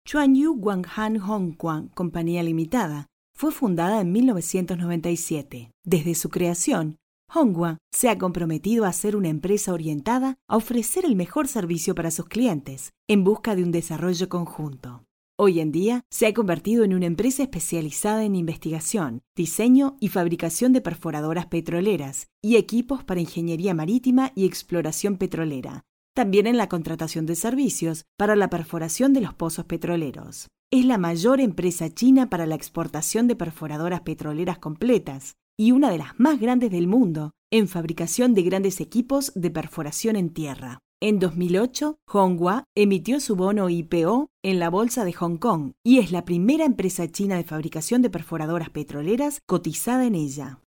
西班牙语样音试听下载
西班牙语配音员（女1）